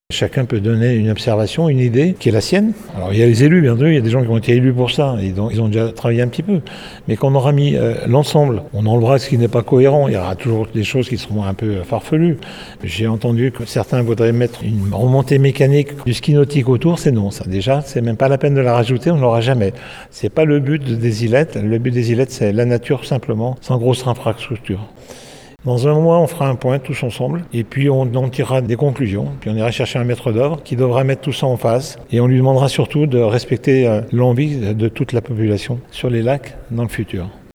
Georges Morand est le maire de Sallanches.